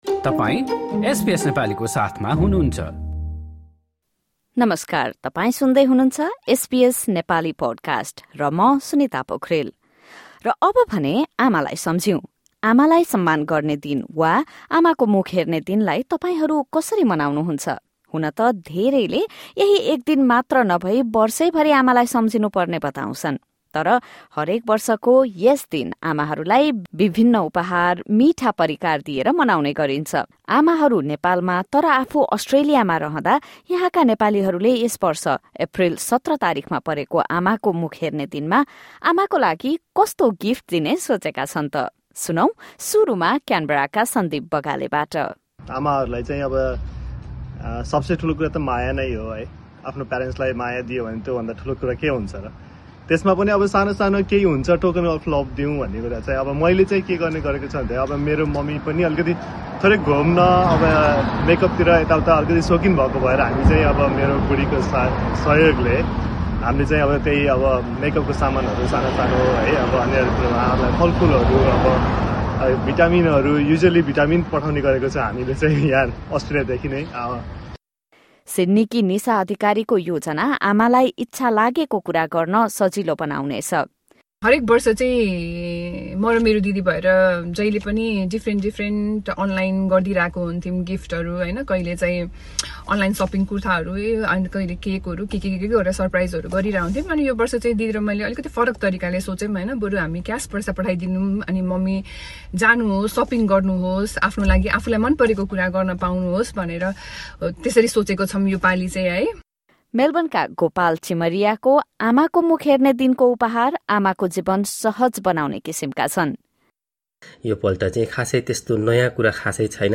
Listen to Nepalis living in Australia as they share their thoughtful gift ideas for their mothers.